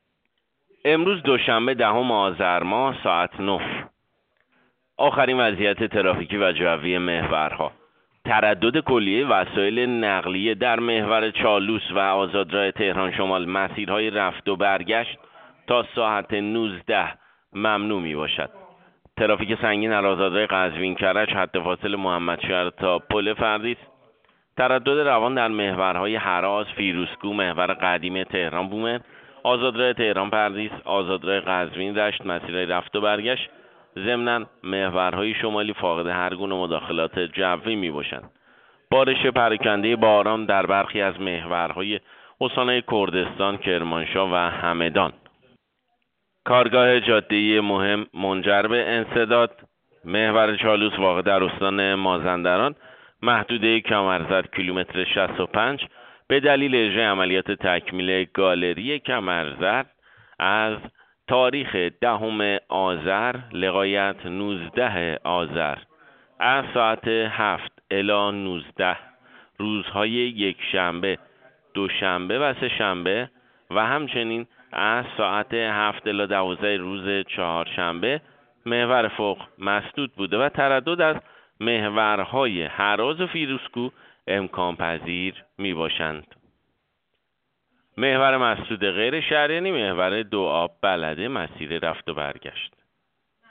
گزارش رادیو اینترنتی از آخرین وضعیت ترافیکی جاده‌ها ساعت ۱۰ آذر؛